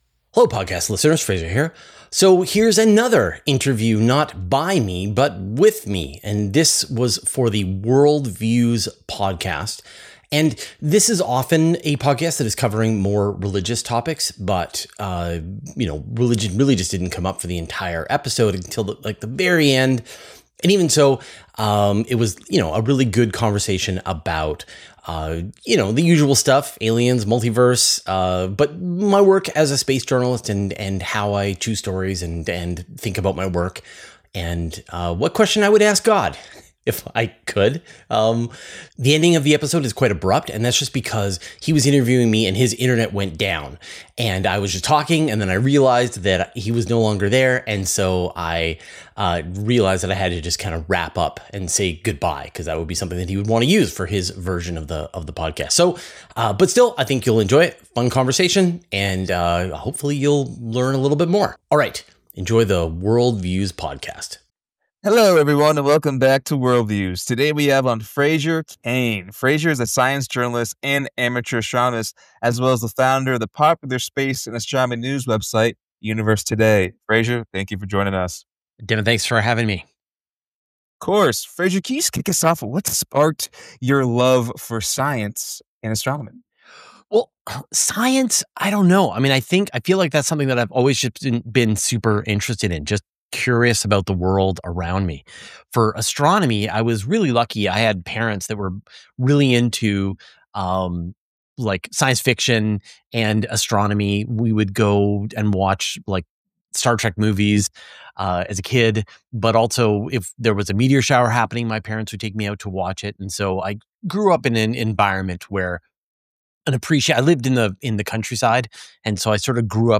This is an interview with me for the Worldviews Podcast.